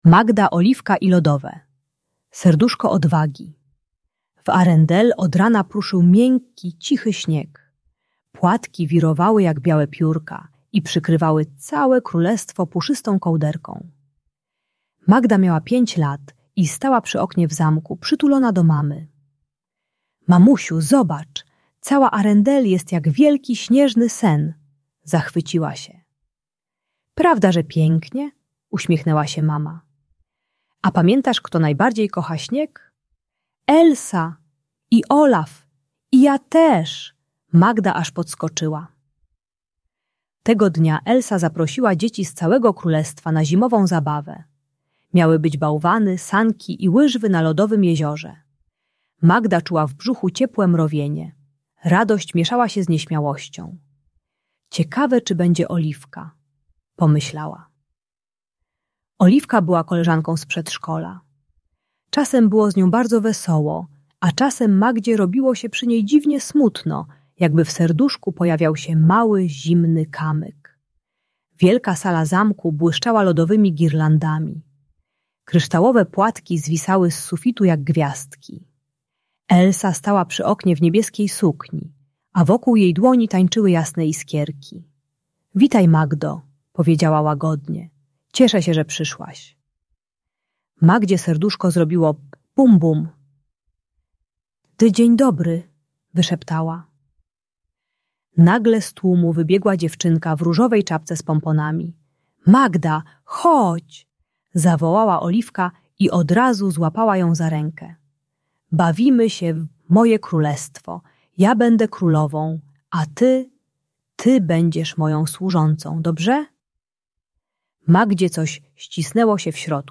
Uczy techniki "Lodowego Serduszka Odwagi" - jak asertywnie stawiać granice, pozostając grzecznym. Audiobajka o asertywności dla nieśmiałych dzieci.